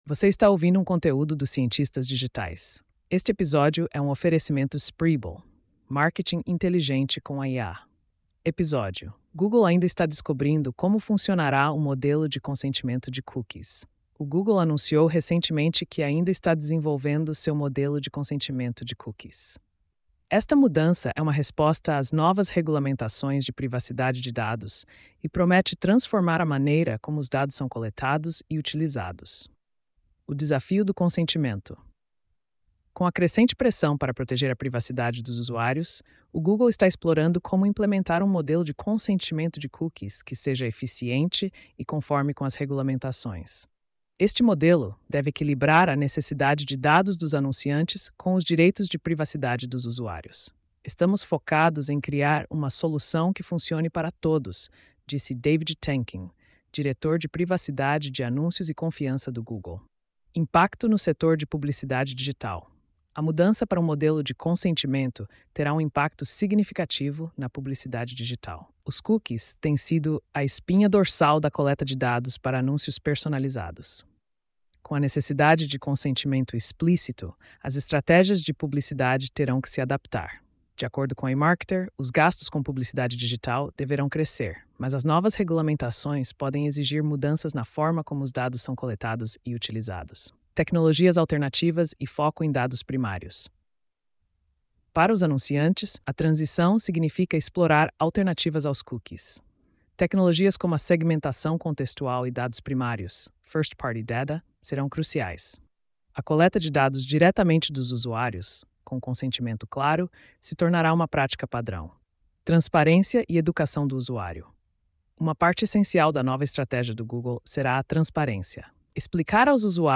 post-2342-tts.mp3